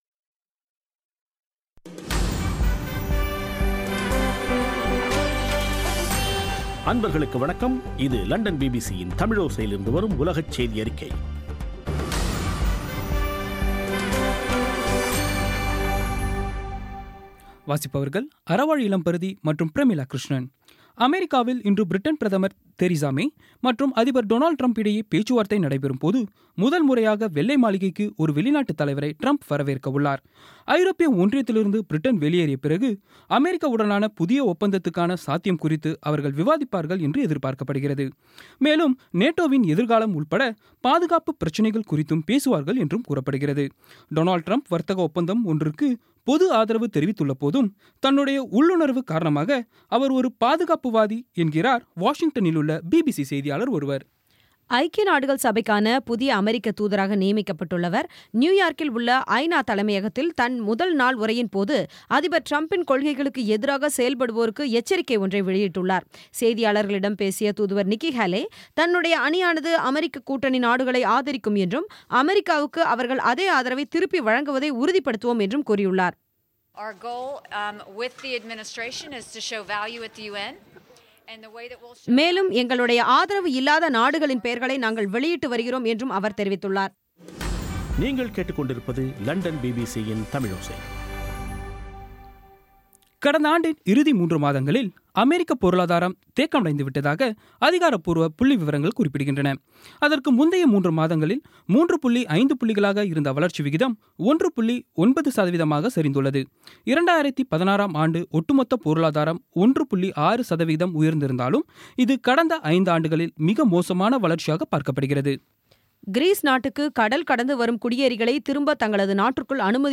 பிபிசி தமிழோசை செய்தியறிக்கை (27/01/2017)